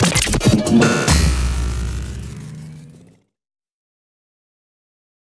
FX – 138 – BREAKER
FX-138-BREAKER.mp3